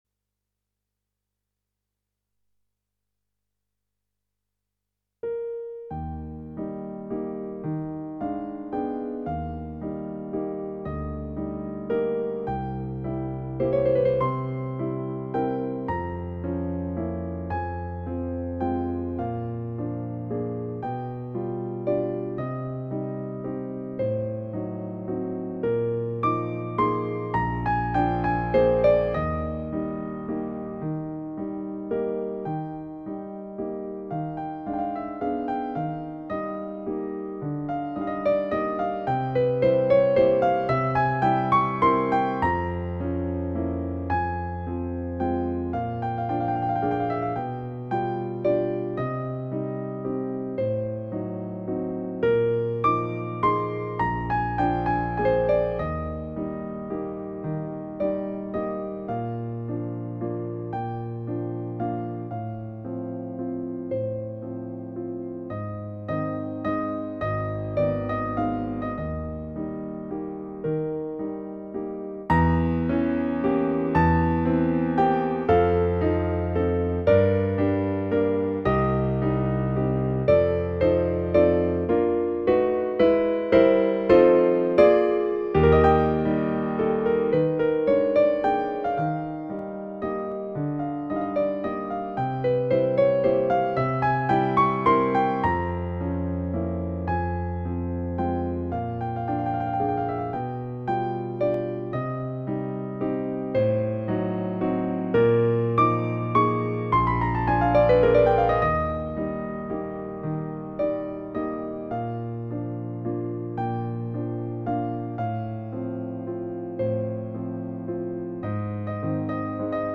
Casio PX-S7000 Test: Innovatives Digitalpiano mit smartem Design
casio_px_s7000_test_privia_gr_hg_demo.mp3